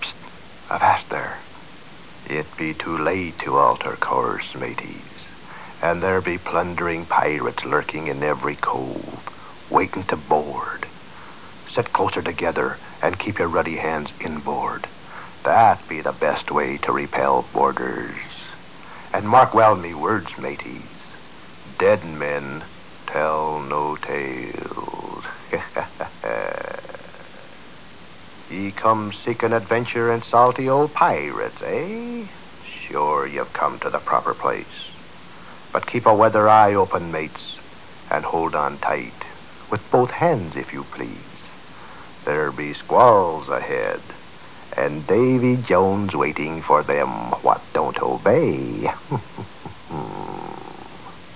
The talking skull from Pirates of the Carribean.